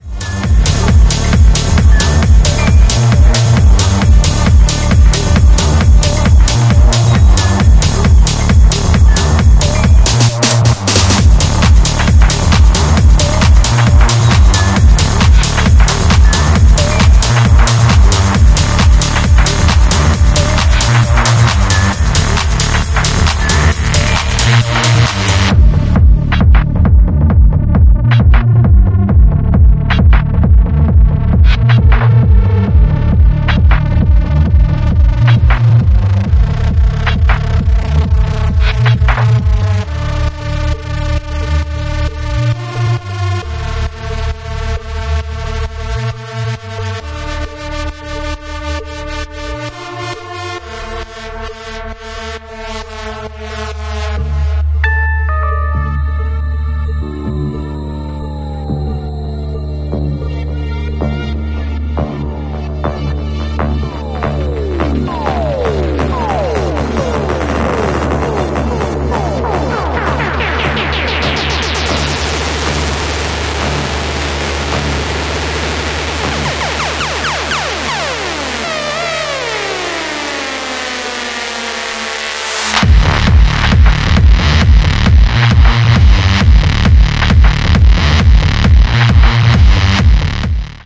rousing riff-driven weapon